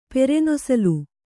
♪ pere nosalu